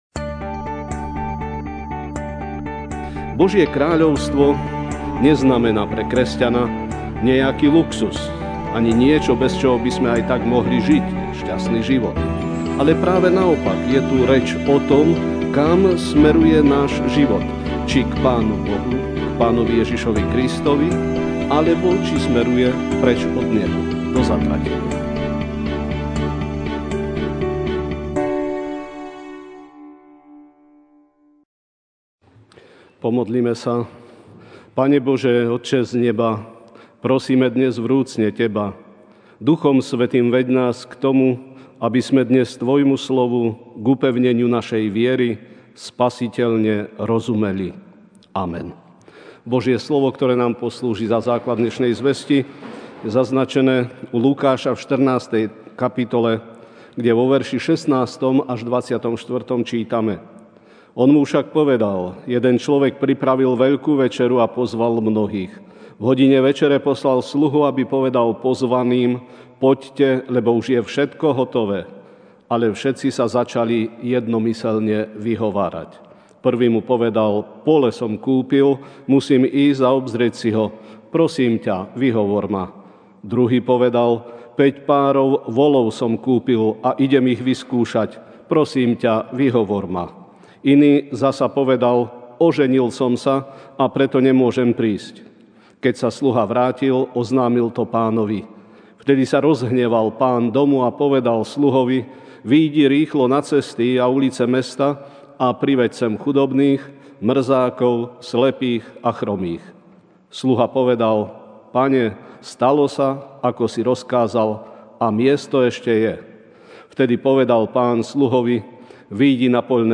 Večerná kázeň:Pozvanie od Pána (L 14, 16-24) On mu však povedal: Jeden človek pripravil veľkú večeru a pozval mnohých.